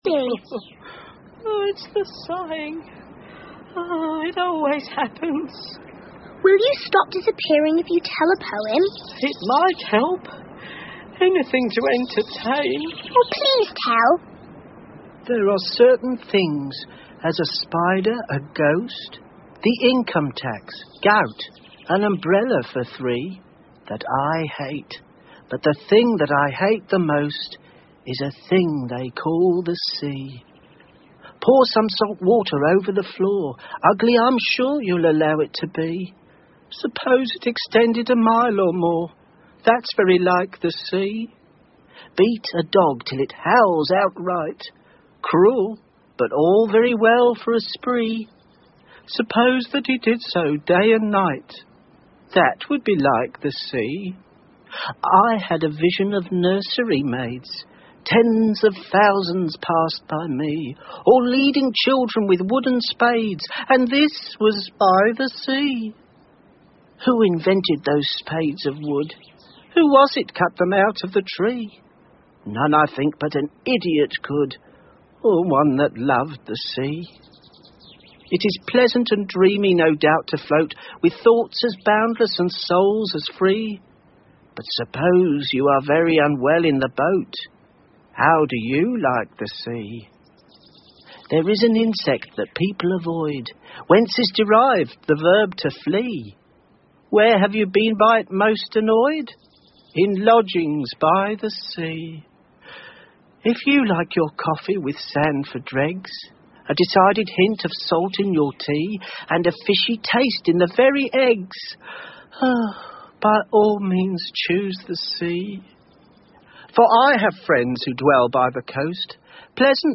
Through The Looking Glas 艾丽丝镜中奇遇记 儿童广播剧 7 听力文件下载—在线英语听力室